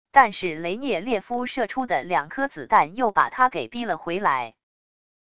但是雷涅列夫射出的两颗子弹又把他给逼了回来wav音频生成系统WAV Audio Player